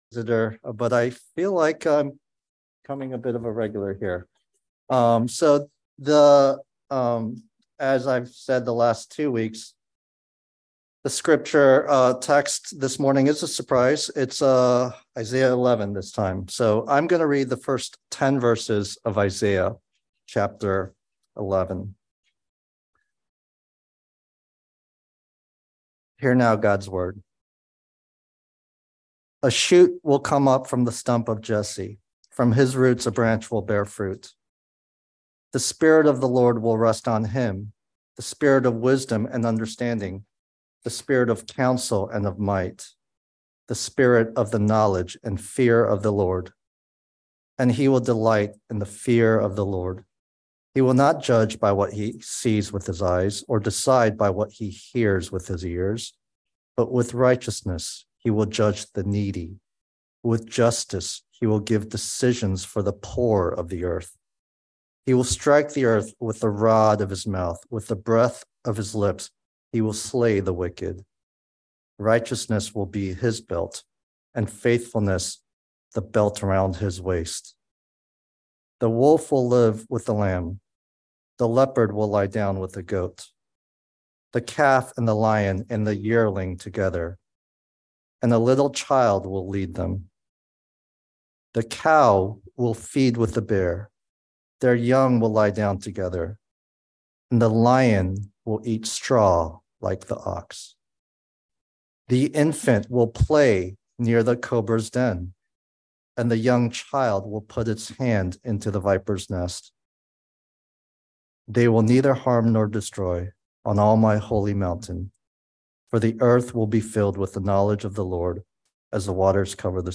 by Trinity Presbyterian Church | Jul 17, 2023 | Sermon